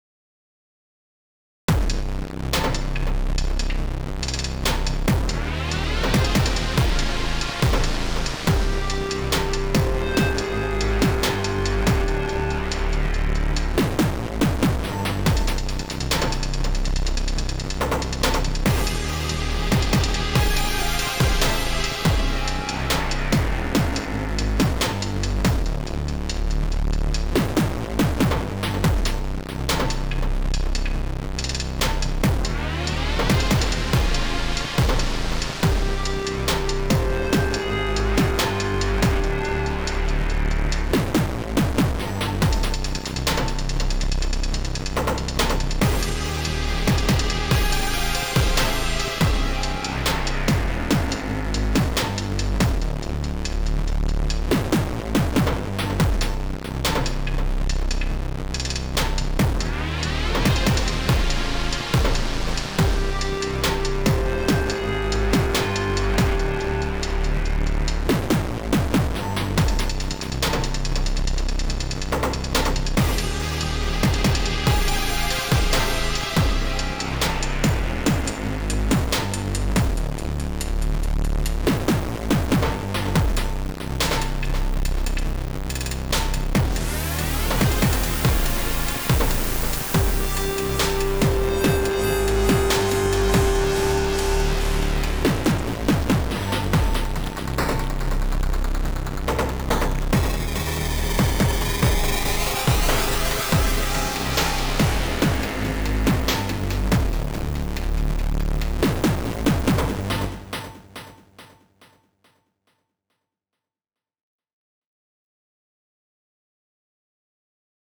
pretty basic pattern, but sound designing went really smooth and I love having a master comp!